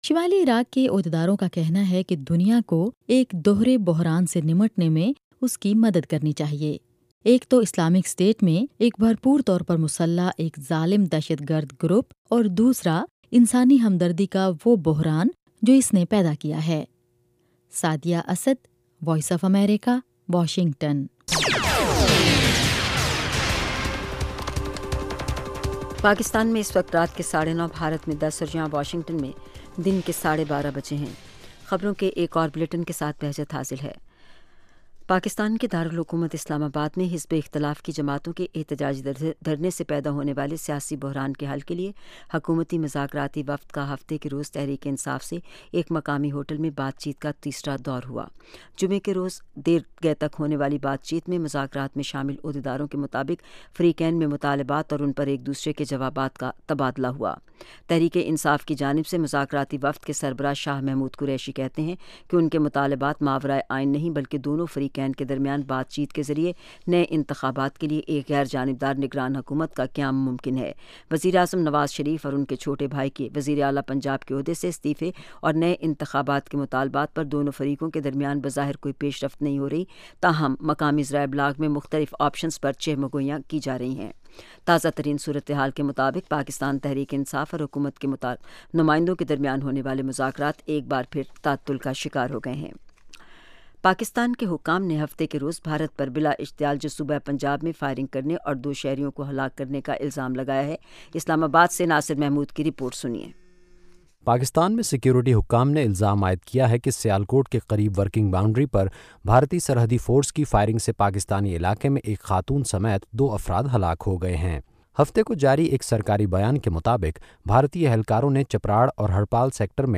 Urdu Headlines